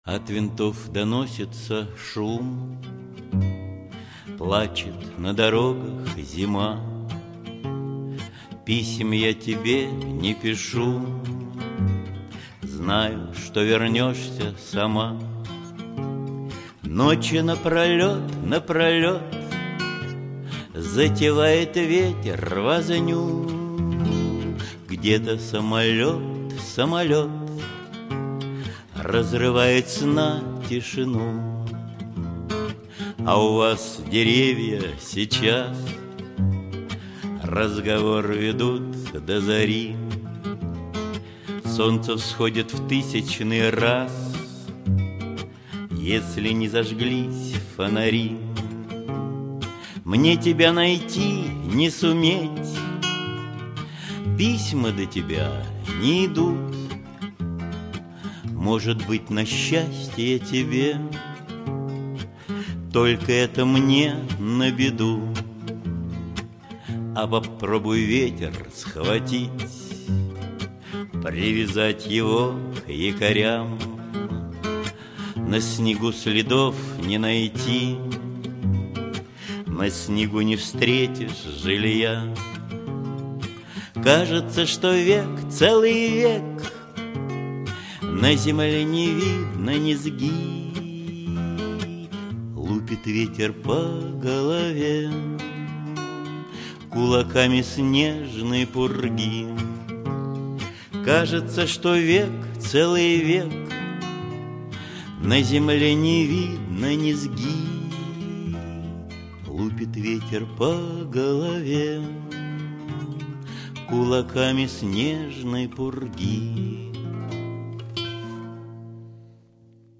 Исполняет автор.